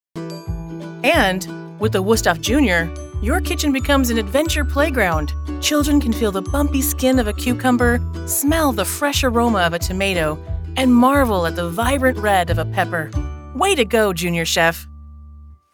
Empathic, Sincere, and Direct - Broadcast ready in San Francisco Bay Area
Middle Aged
I sound like the boss who is looking out for you, a reliable friend who keeps you informed, or a voice of calm when you need it most.